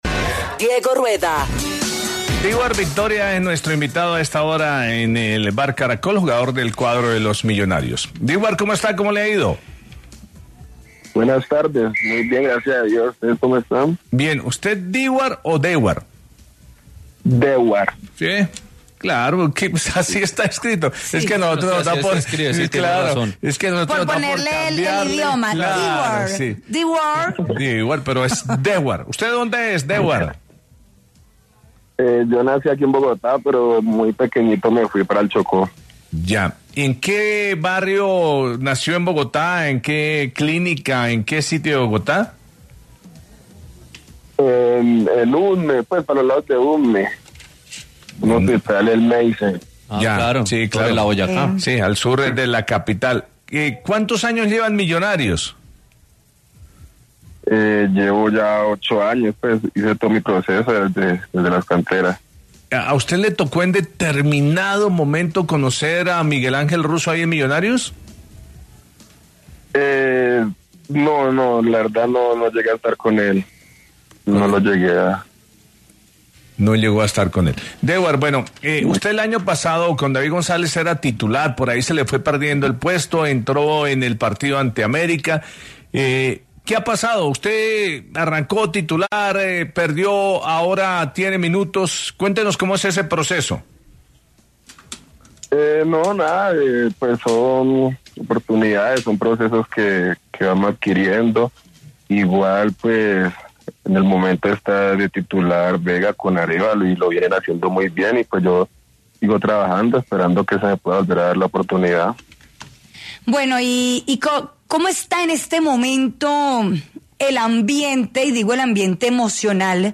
¿Cómo va su presente en Millonarios?